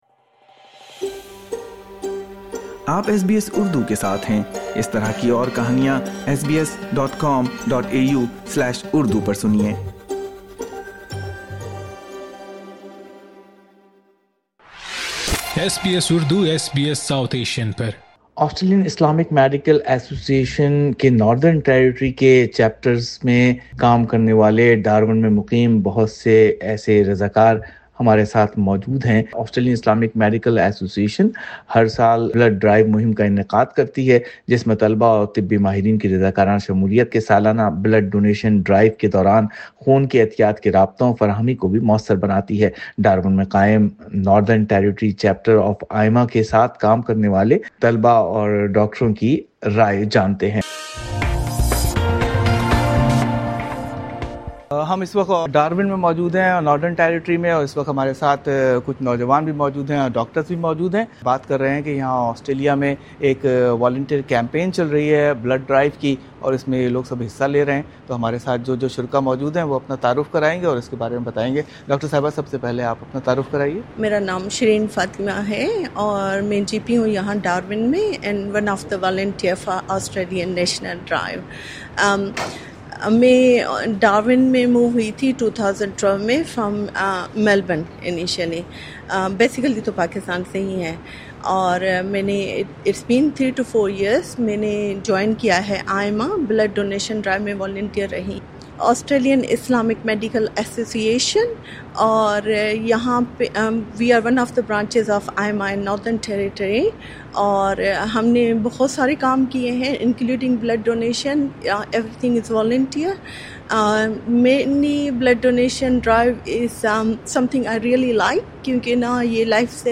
LISTEN TO Lifelines of compassion: Young volunteers power AIMA’s blood drive in the Northern Territory SBS Urdu 10:52 Urdu In this podcast, listen to a conversation with volunteers collaborating with the Darwin-based Northern Territory chapter of the Australian Islamic Medical Association (AIMA), a leading organisation of Muslim healthcare professionals.